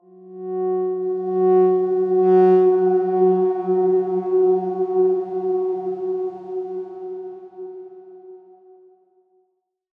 X_Darkswarm-F#3-pp.wav